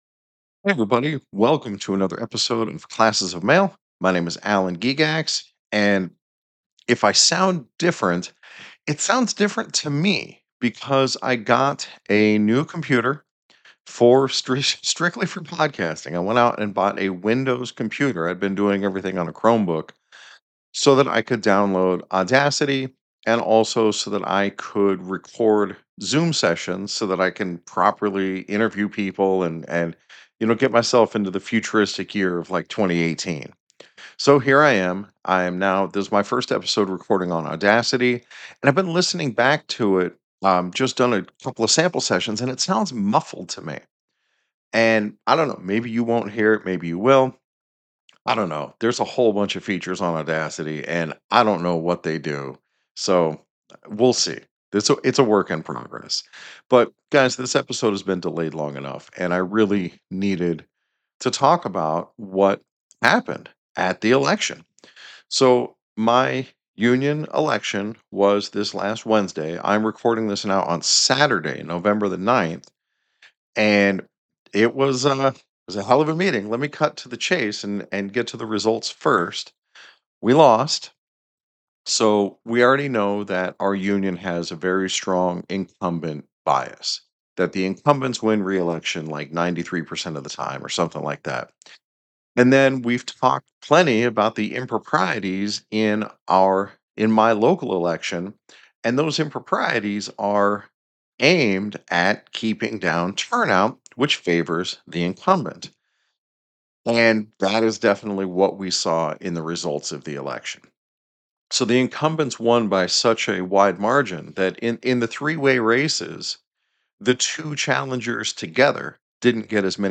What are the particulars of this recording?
I recorded on Audacity for the first time. It sounds really compressed and muffled to me.